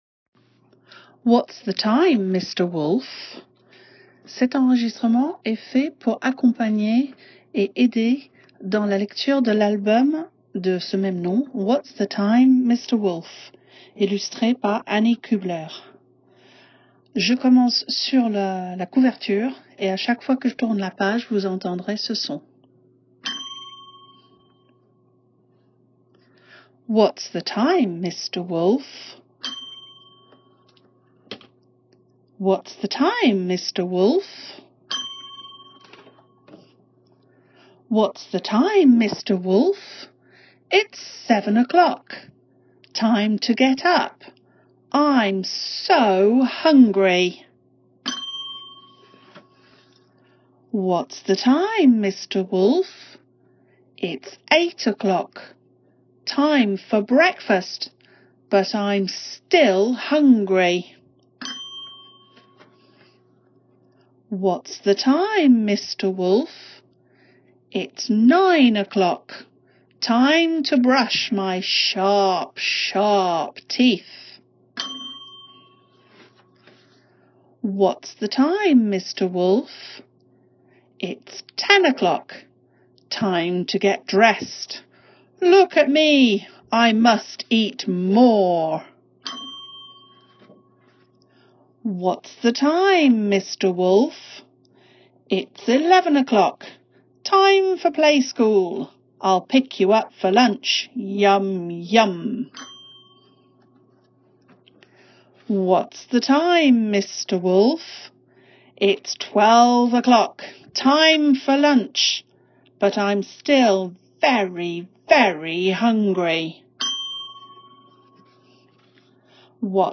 telling-a-story-whats-the-time-mr-wolf.m4a